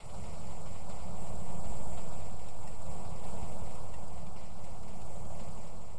Rain2.wav